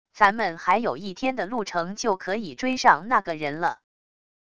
咱们还有一天的路程就可以追上那个人了wav音频生成系统WAV Audio Player